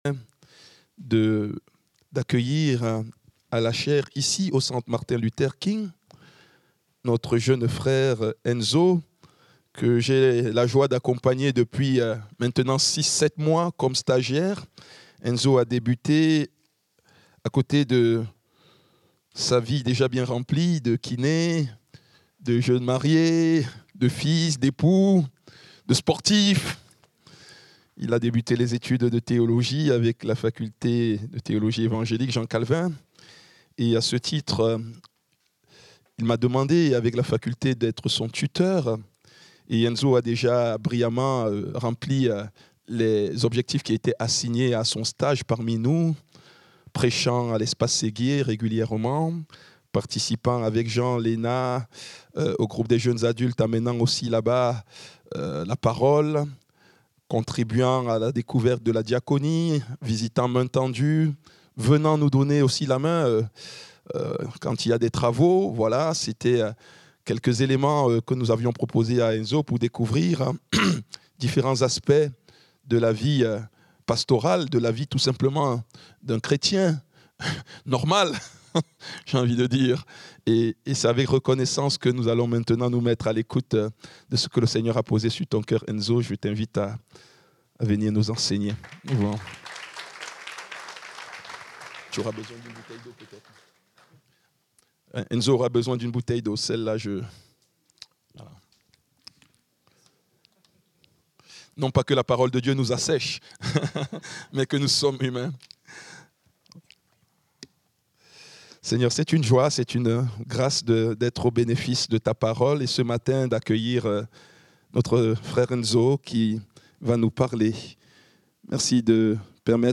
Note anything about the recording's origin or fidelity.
Culte du dimanche 01 juin 2025